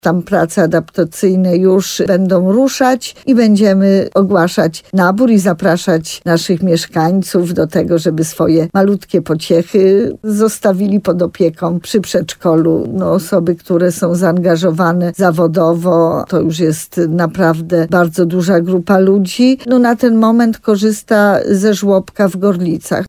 Od 1 stycznia będzie można zgłaszać się do naboru – powiedziała w Słowo za Słowo w RDN Nowy Sącz Małgorzata Małuch, wójt Sękowej.